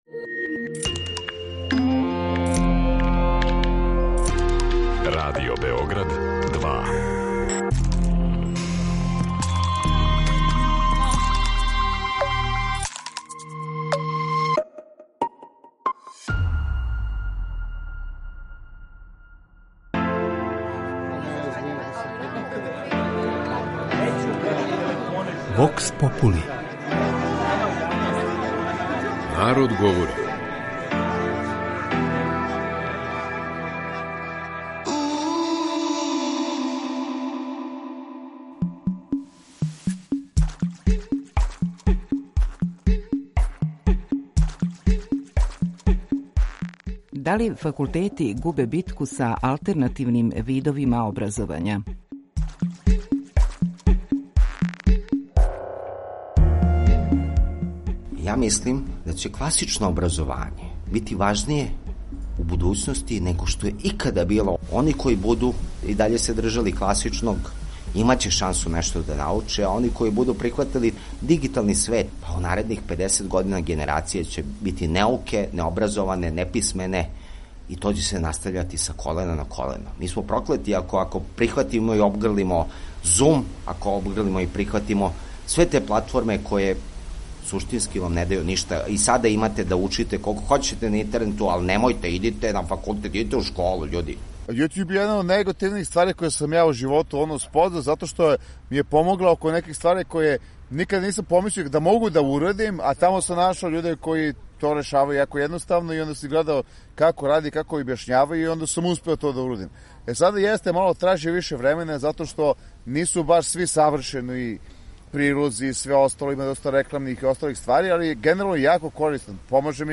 voks.mp3